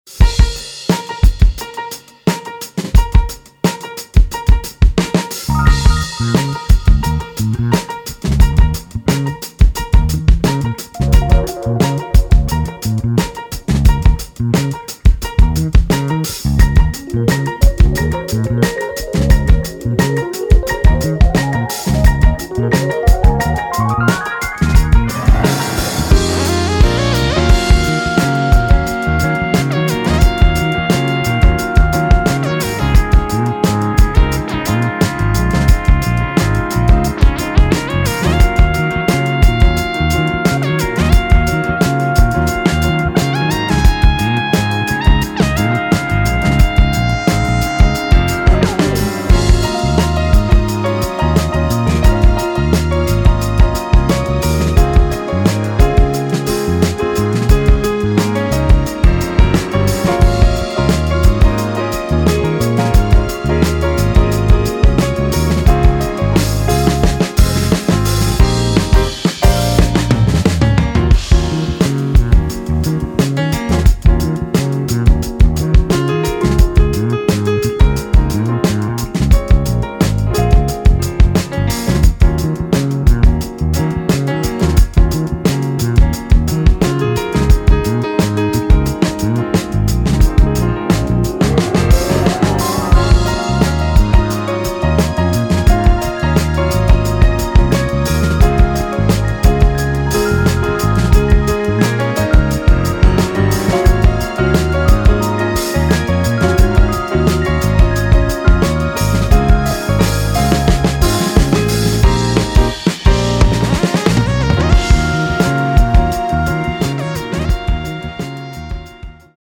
a piano trio